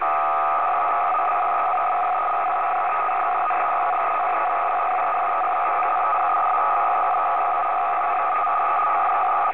Неизвестный сигнал
8803khzusb.wav